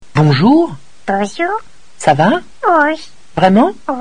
Messages pour répondeur
Lebip.mp3